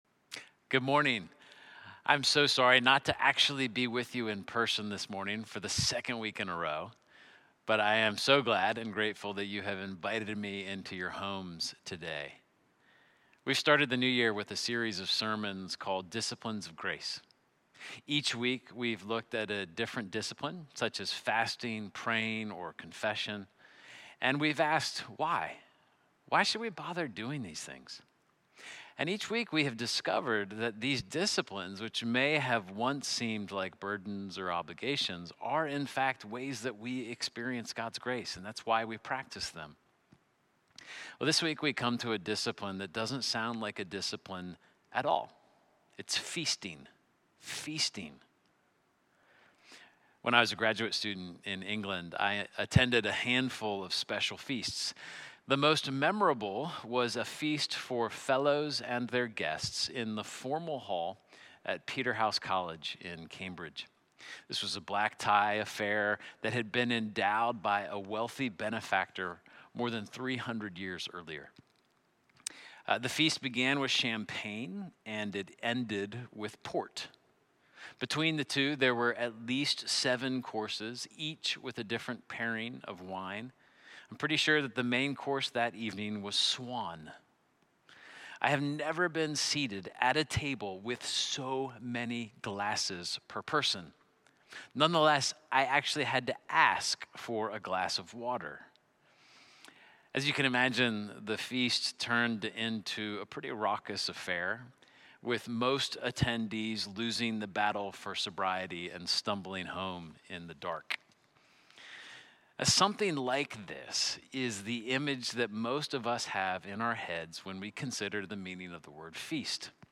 Sermons - Holy Trinity Anglican Church
Snowday-Sermon-2.mp3